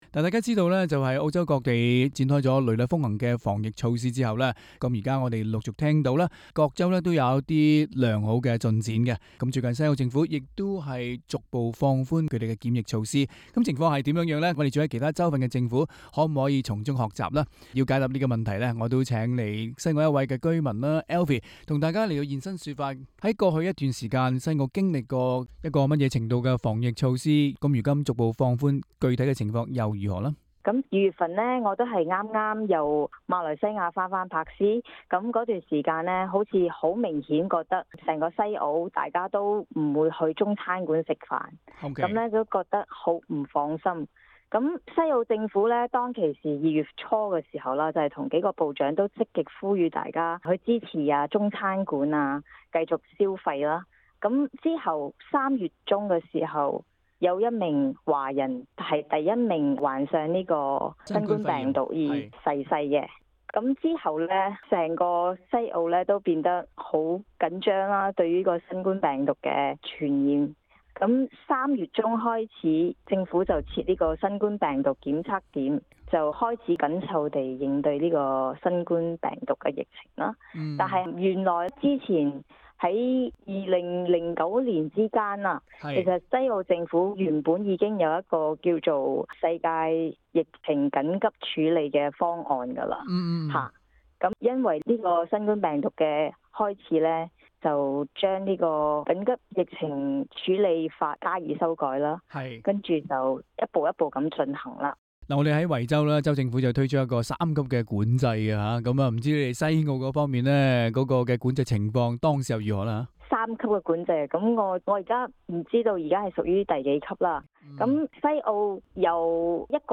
Source: Wikimedia Commons SBS廣東話節目 View Podcast Series Follow and Subscribe Apple Podcasts YouTube Spotify Download (19.41MB) Download the SBS Audio app Available on iOS and Android 西澳州政府打擊疫情取得良好成績，現時學校已經復課。